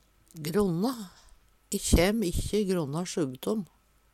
gronna - Numedalsmål (en-US)